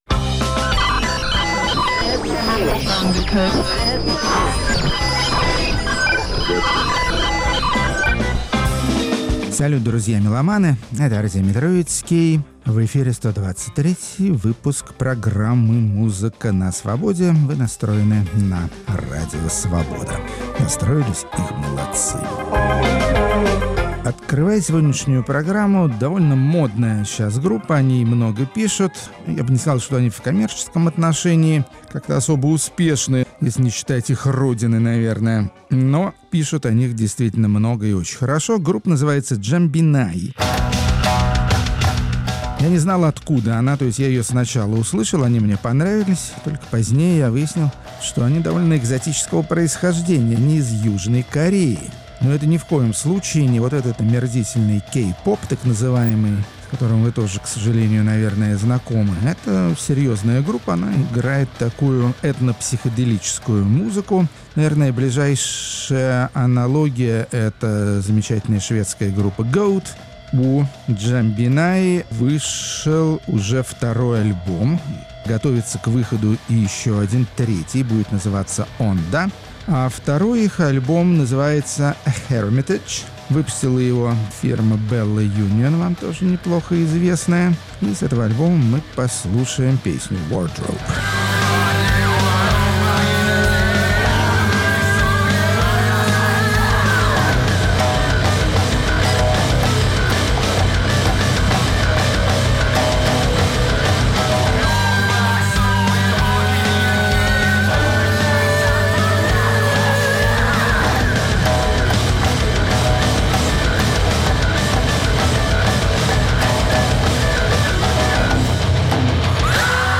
Рок-критик Артемий Троицкий воздаёт ему по заслугам.